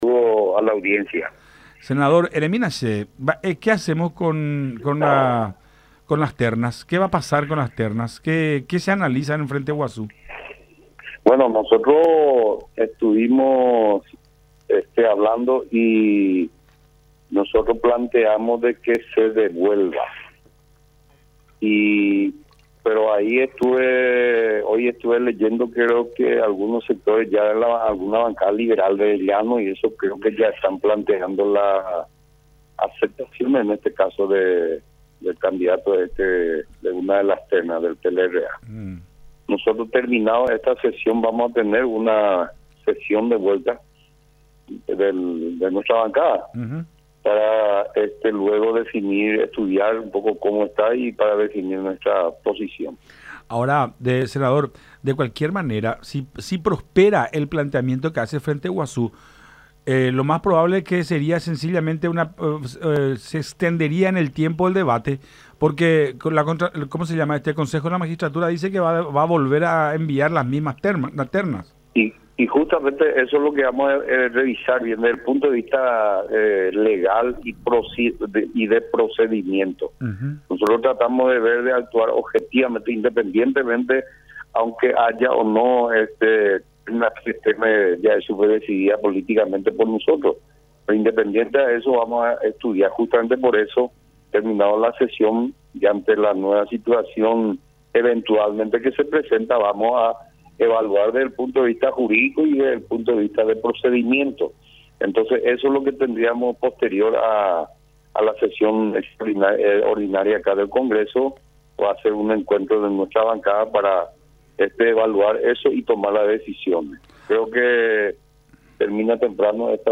Por eso, vamos a evaluar eso hoy en bancada posterior a la sesión del Congreso”, dijo el parlamentario en conversación con Todas Las Voces a través de La Unión.